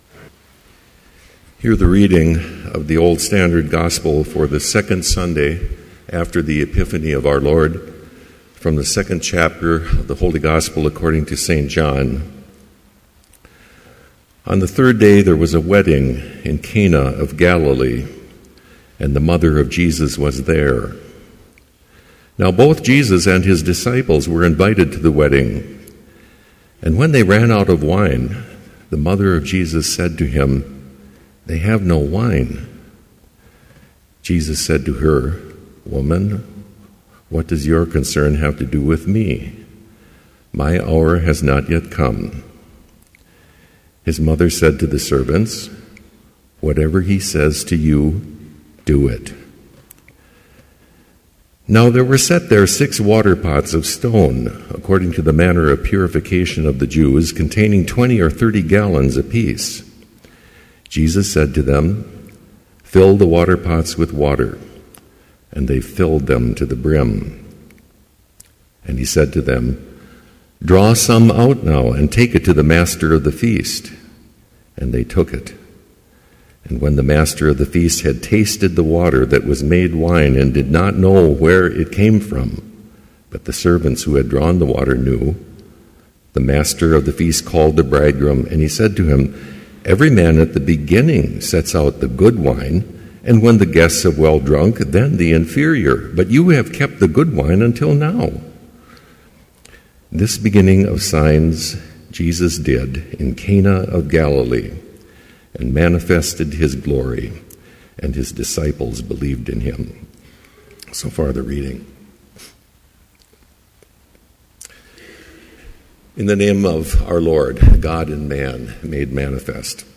Complete Service
This Chapel Service was held in Trinity Chapel at Bethany Lutheran College on Wednesday, January 22, 2014, at 10 a.m. Page and hymn numbers are from the Evangelical Lutheran Hymnary.